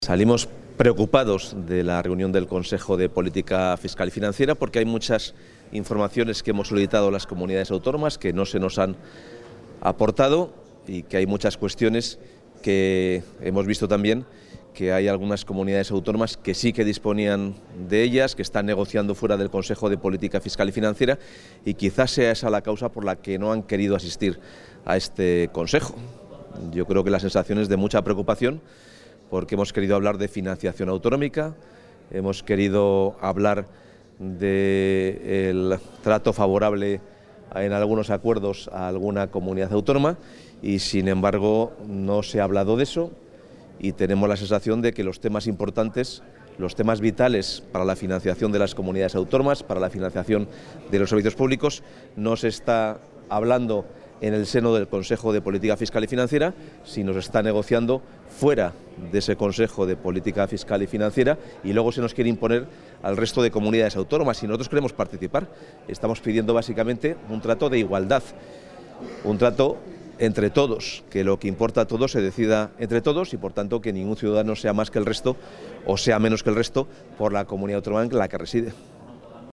Declaraciones del consejero de Economía y Hacienda tras la celebración del Consejo de Política Fiscal y Financiera | Comunicación | Junta de Castilla y León
Se adjunta archivo de audio con las declaraciones del consejero de Economía y Hacienda, Carlos Fernández Carriedo, tras participar en la reunión del Consejo de Política Fiscal y Financiera.